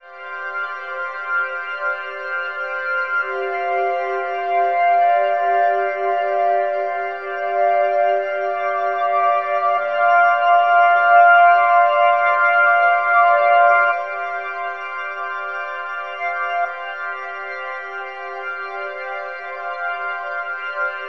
60.1ETHPAD.wav